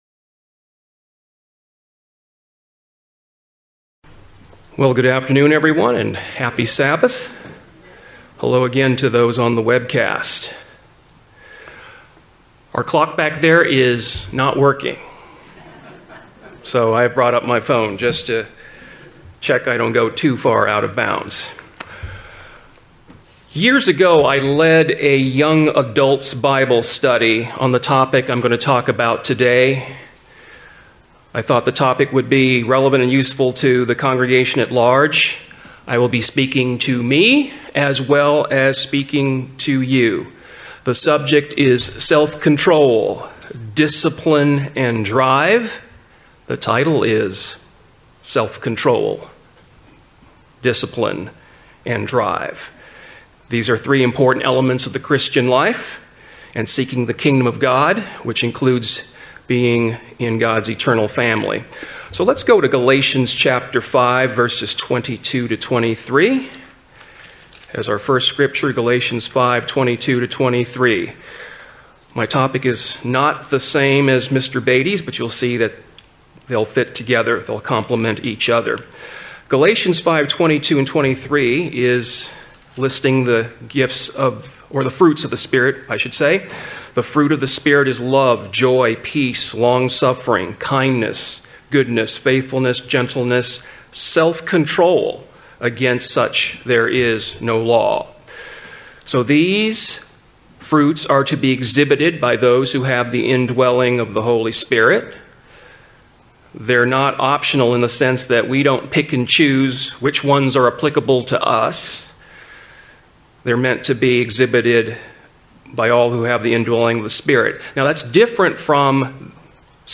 What originally was a Bible Study aimed at teens and young adults was turned into a message for the congregation as a whole. The subject is self-control, discipline, and drive. All three are important elements of the Christian life and seeking the Kingdom of God.